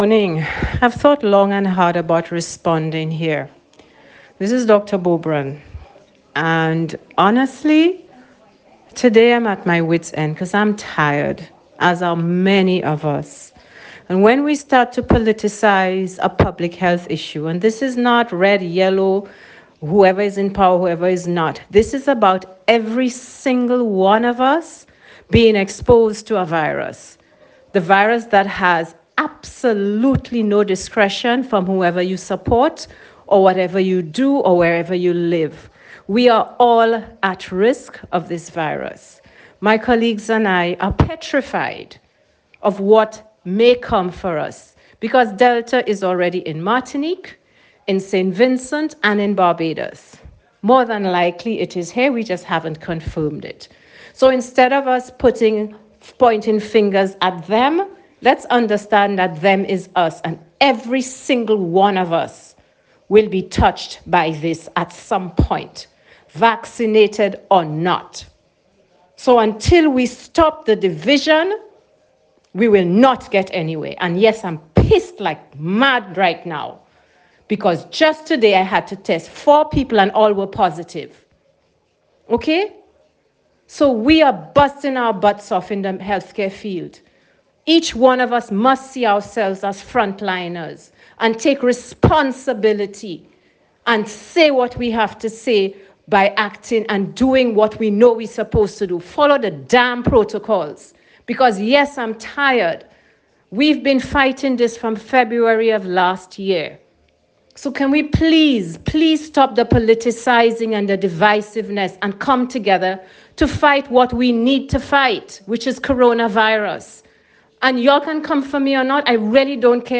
A-Doctor-Speaks.ogg